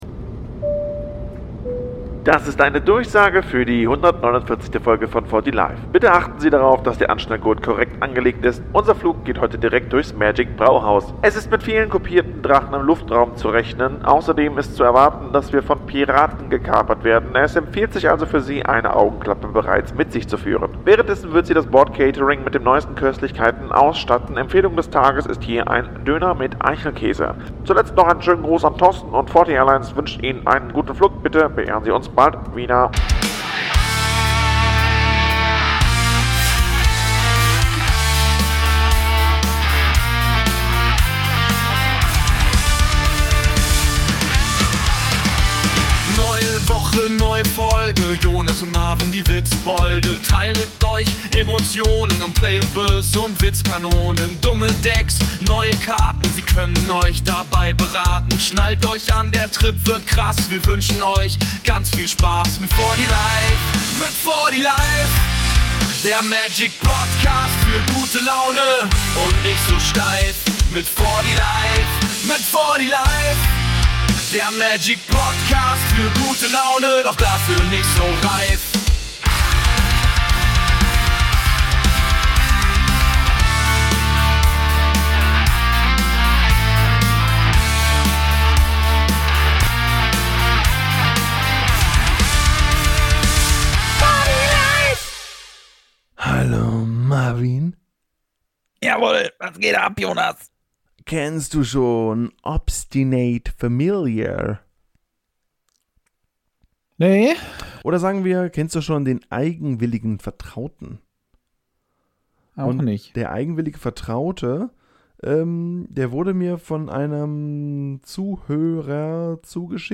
Während alle aus der Ritze schwitzen, erfrischen wir uns und euch im Brauhaus. Gereicht zum kühlen Blonden wird ein Döner mit Spezialzutat. Außerdem gibt's weitere Infos zu unseren Plänen für die Sommerferien.